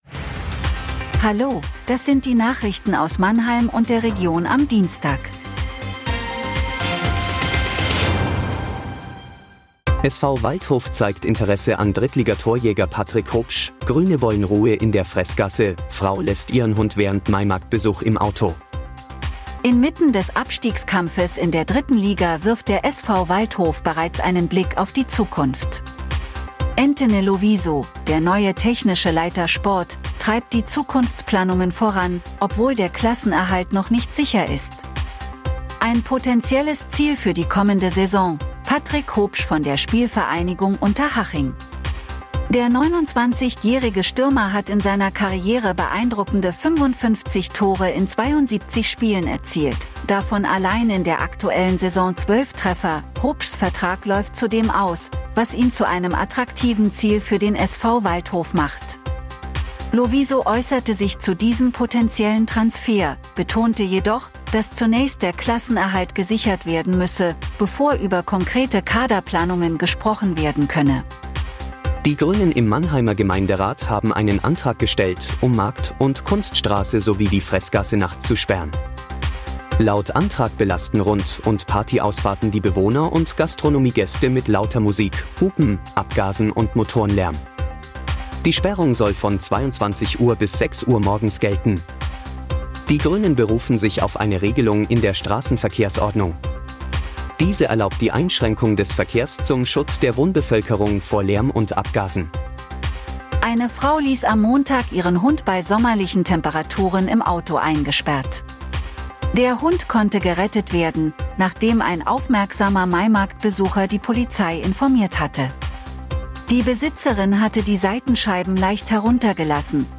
Der Nachrichten-Podcast des MANNHEIMER MORGEN
Nachrichten